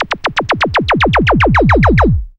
DISCO ZAPP.wav